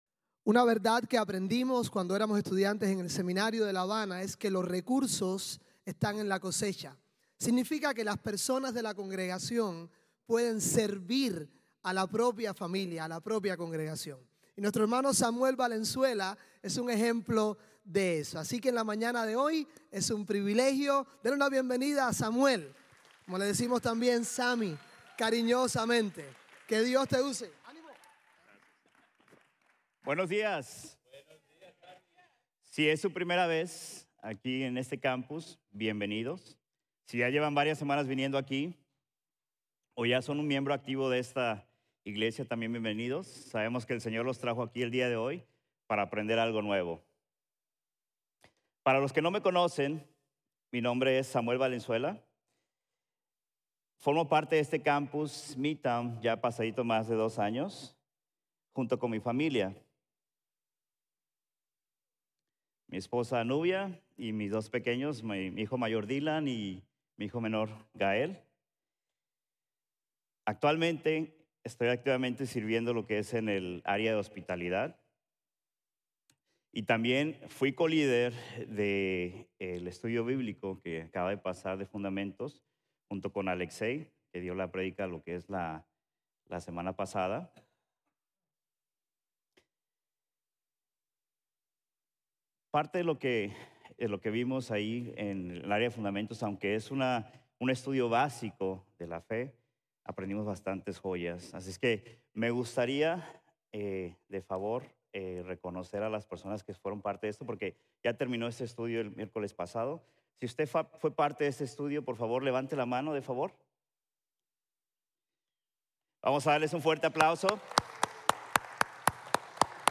El Buen Pastor | Sermon | Grace Bible Church